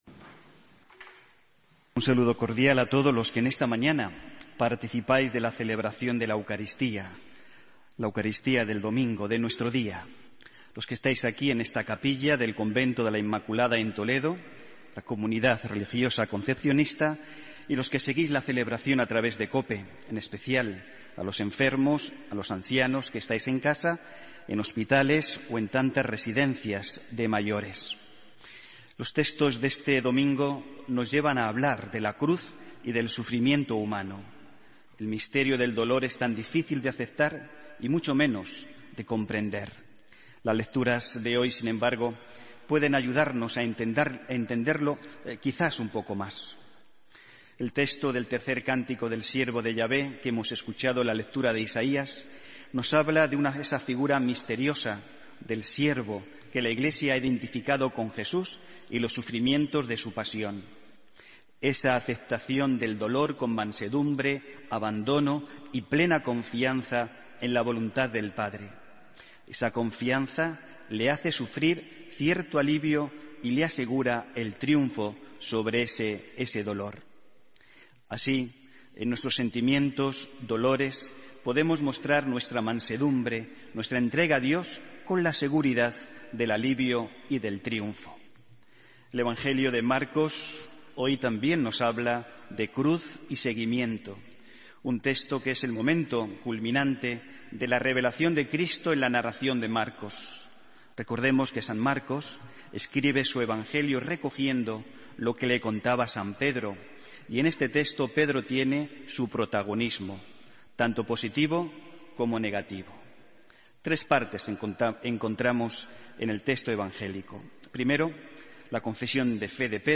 Homilía del domingo 13 de septiembre de 2015